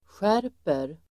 Uttal: [sj'är:per]